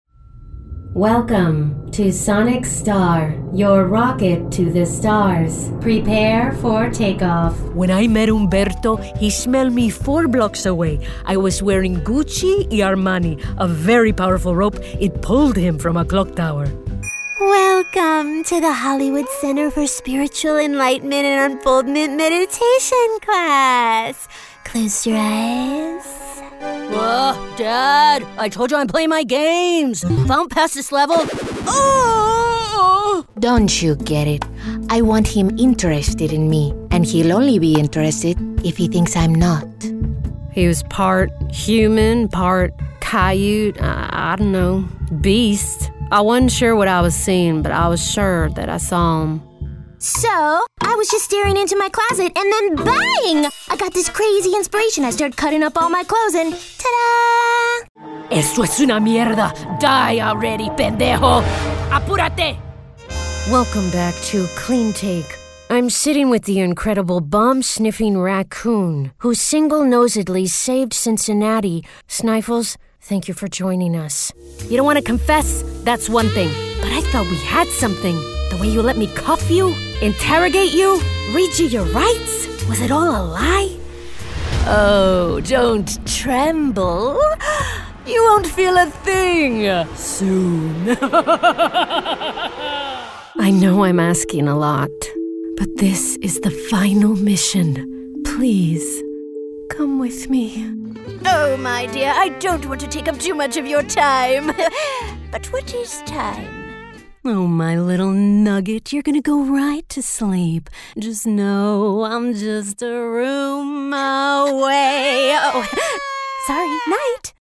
Voiceover
Animation Demo
Animation-Demo-New.mp3